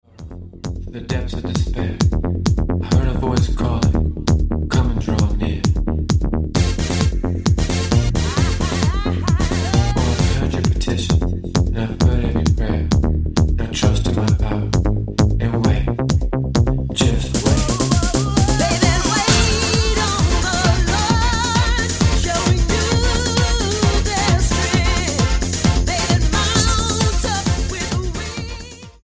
Style: Dance/Electronic Approach: Praise & Worship